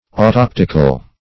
Definition of autoptical.
Search Result for " autoptical" : The Collaborative International Dictionary of English v.0.48: Autoptic \Au*top"tic\ ([add]*t[o^]p"t[i^]k), Autoptical \Au*top"tic*al\ (-t[i^]k*al), a. [Gr. a'ytoptiko`s: cf. F. autoptique.]